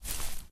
FootstepGrass02.ogg